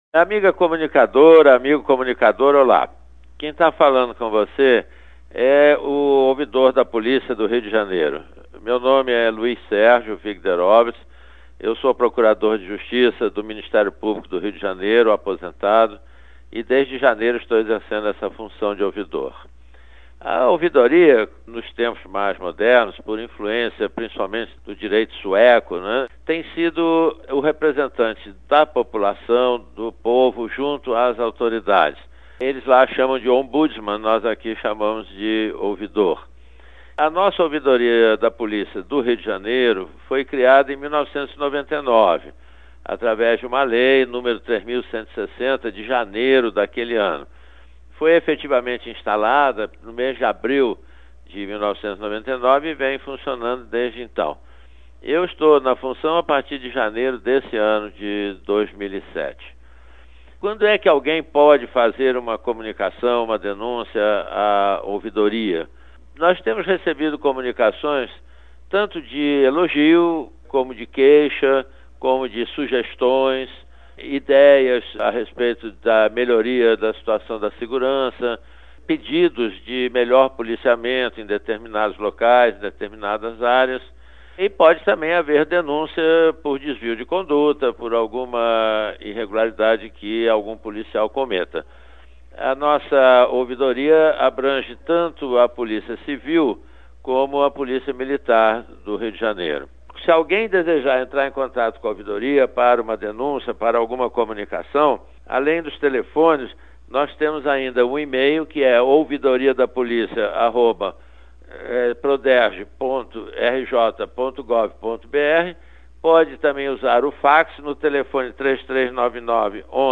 Carta Falada